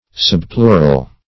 \Sub*pleu"ral\